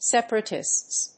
/ˈsɛpɝʌtɪsts(米国英語), ˈsepɜ:ʌtɪsts(英国英語)/